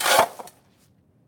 dig2.ogg